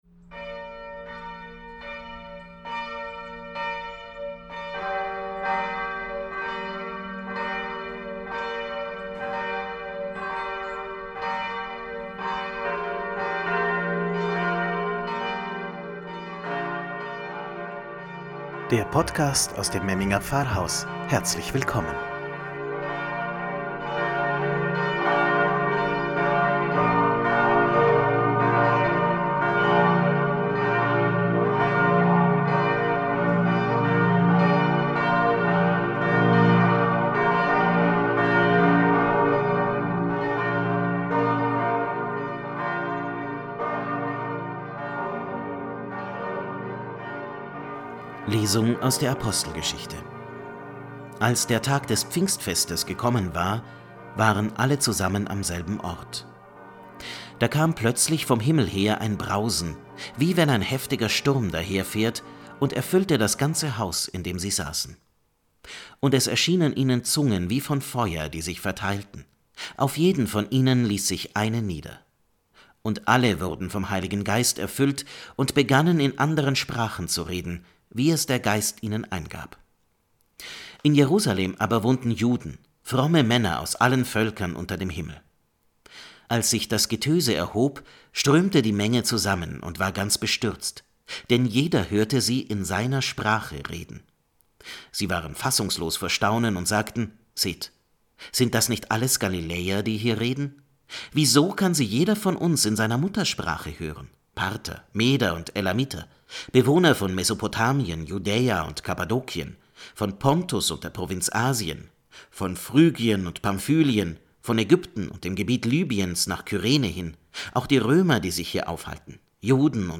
„Wort zum Sonntag“ aus dem Memminger Pfarrhaus – Pfingsten 2021